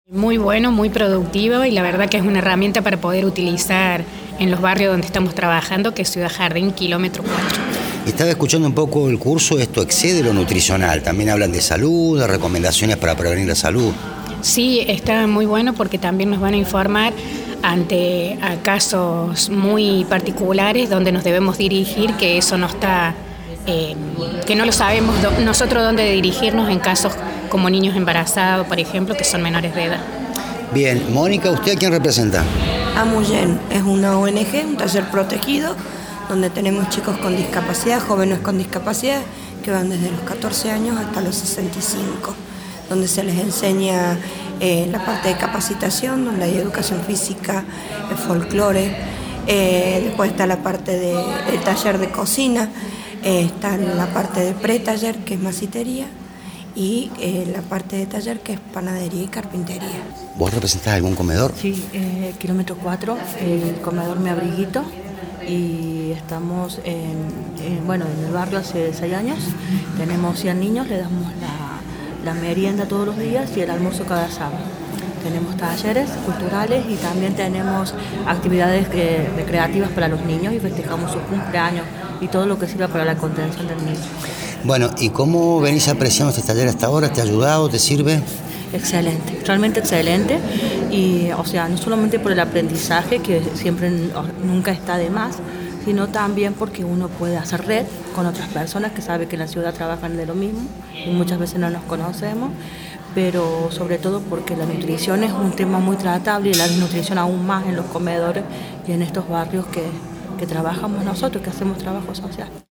Radio UNViMe 87.9 dialogó con distintos protagonistas.
Testimonios de asistentes